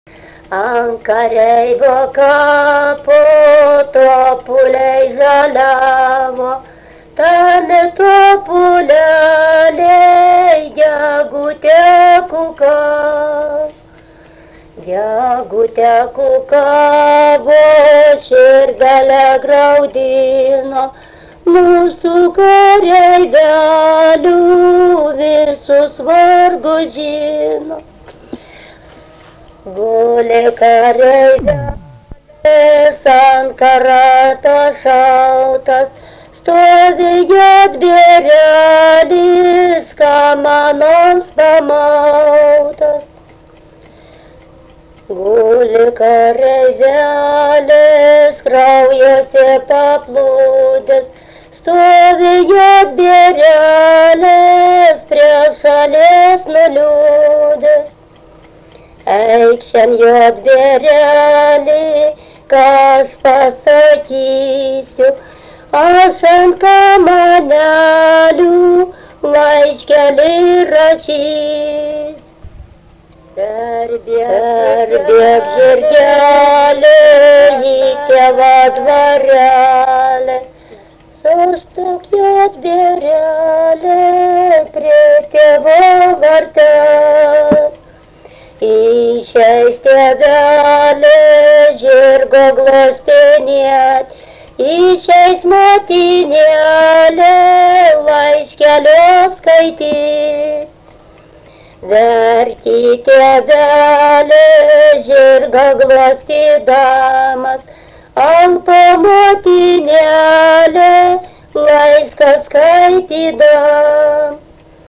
daina, vaikų
Atlikimo pubūdis vokalinis
Du kartus dainuoja, pirmą kartą dubliuojasi du įrašai